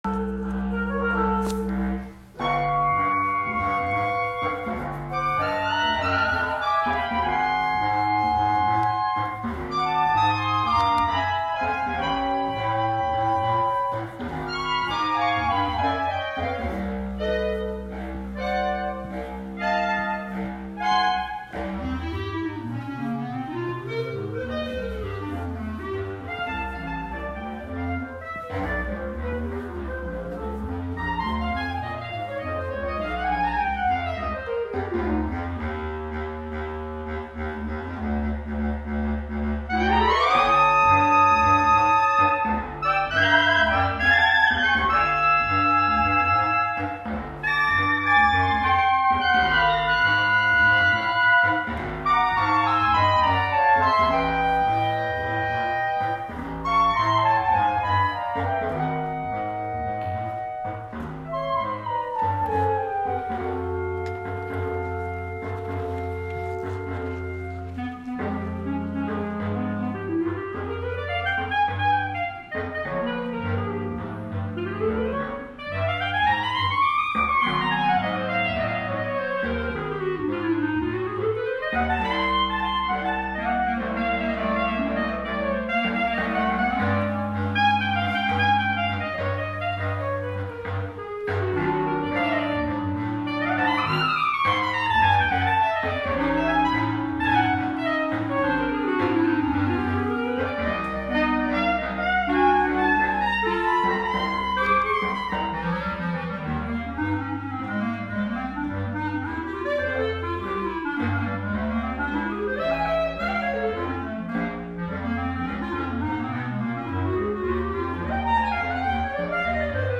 Klee, ‘Hall of Singers’; Mies Van der Rohe, ‘Model small city — concert hall’; City of Palo Alto, Clarinet Thing at Mitchell Park presented by Earthwise February 25, 2020